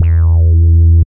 71.06 BASS.wav